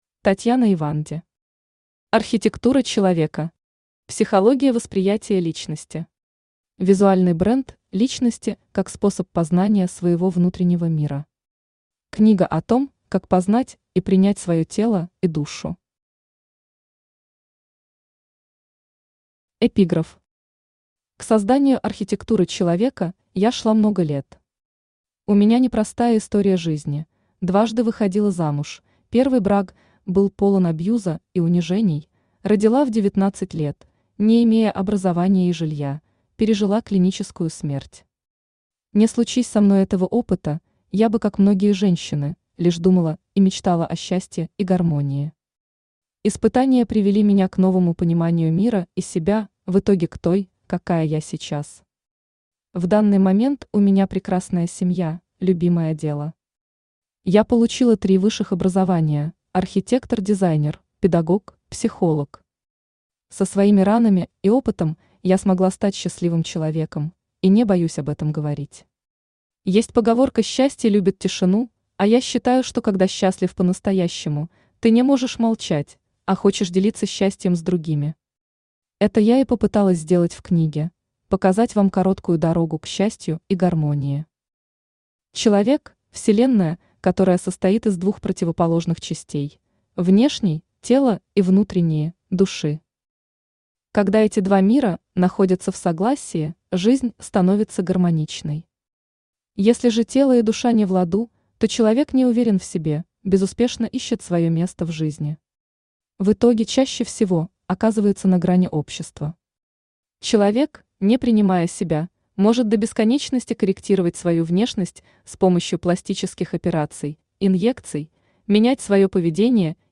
Аудиокнига Архитектура человека. Психология восприятия личности. Визуальный бренд личности как способ познания своего внутреннего мира. Книга о том, как познать и принять свое тело и душу. | Библиотека аудиокниг